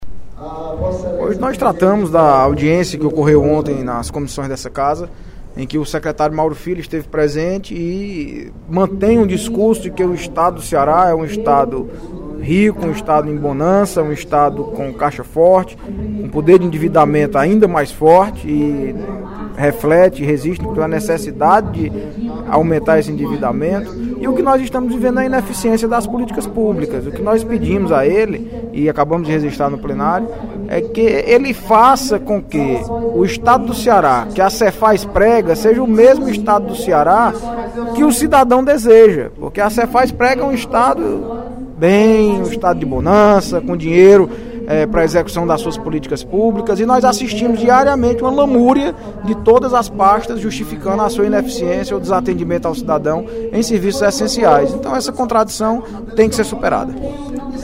O deputado Audic Mota (PMDB) destacou, durante o segundo expediente da sessão plenária desta terça-feira (01/03), a audiência pública realizada ontem, pela Comissão de Orçamento, Finanças e Tributação da AL, para avaliar o cumprimento das metas fiscais do Estado no 3º quadrimestre de 2015.